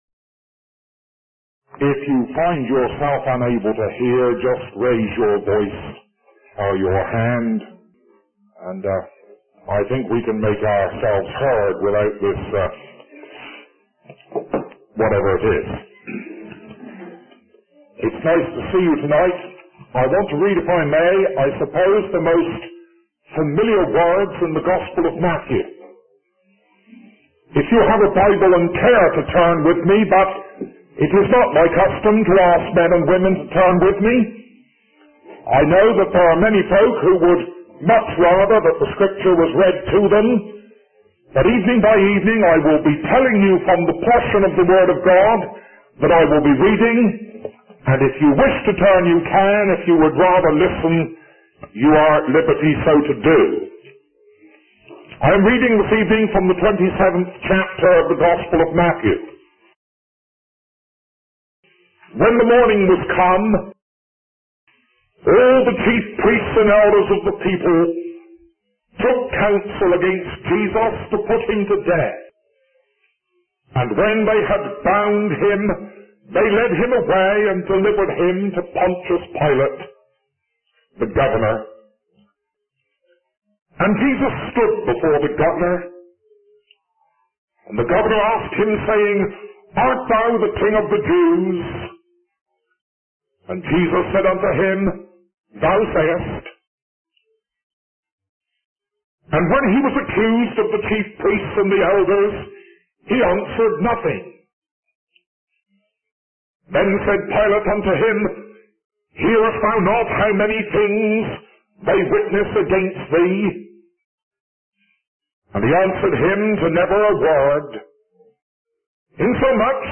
In this sermon, the speaker begins by talking about a hypothetical scenario where the world's greatest pianist sits down to play on a toy piano instead of a grand piano.